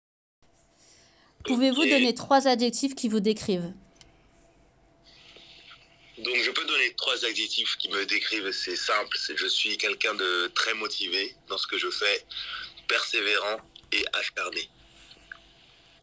Interview :